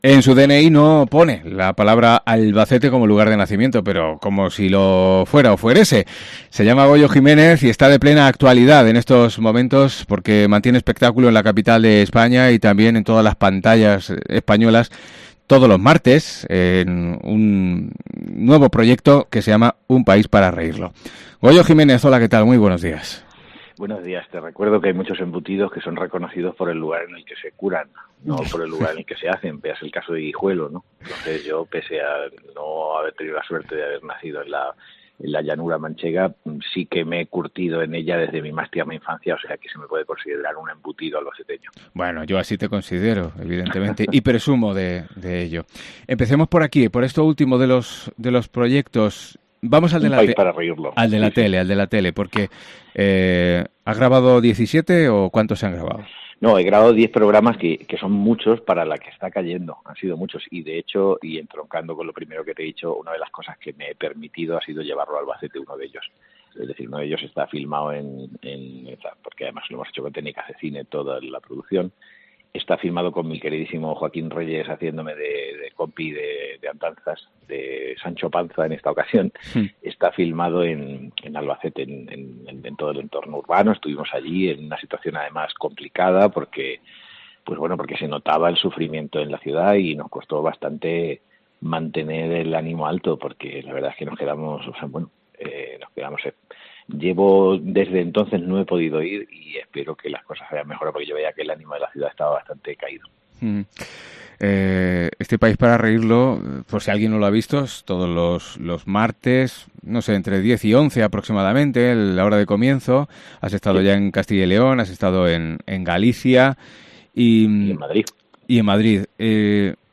El video del monologuista no tiene desperdicio como podrás comprobar, pero además, en la entrevista adjunta comprobarás que Goyo Jiménez es plenamente " ADN Albacete " y que es la manera que tienen las emisoras del grupo COPE para proclamar su orgullo de formar parte de este territorio único.